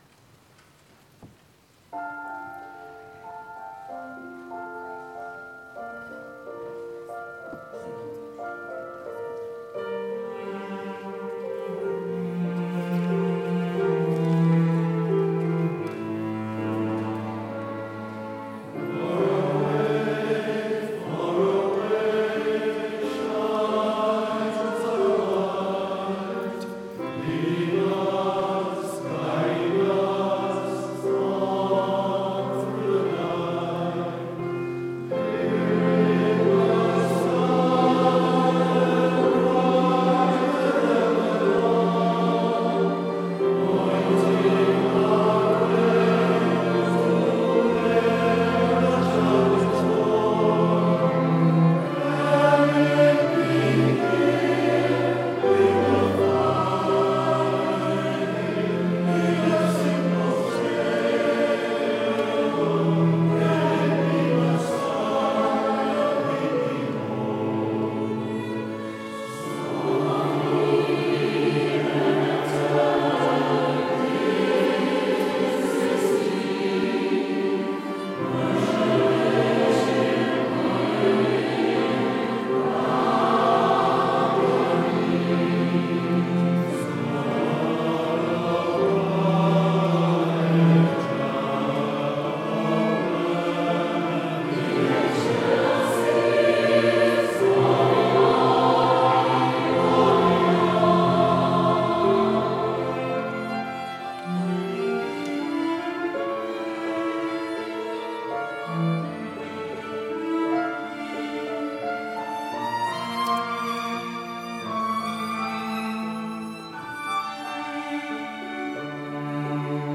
Cathedral Choir, Living Word, Angelorum, Cathedral Clergy Choir, Diocesan Choir, December, 2023